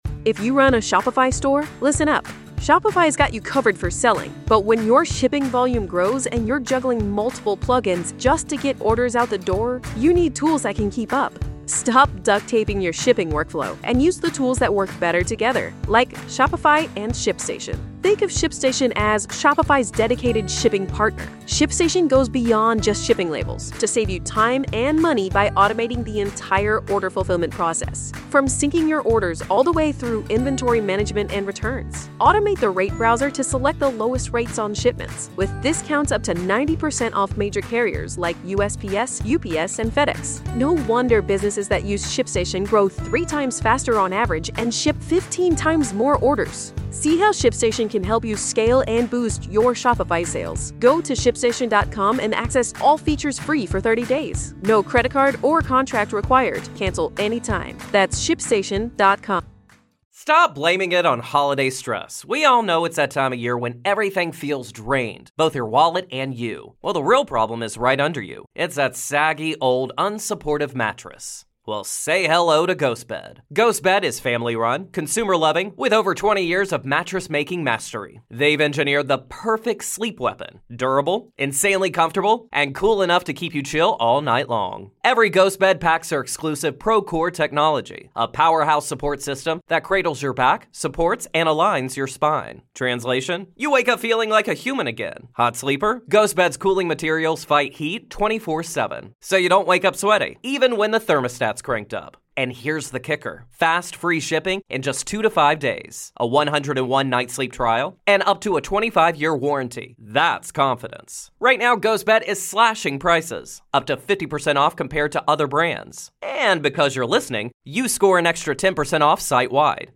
Listen back in Scott Ferrall's radio career with Part 1 of 2 of an August 98 show where Scott talks about Mario Lemieux possibly saving the Penguins and what's going on in the MLB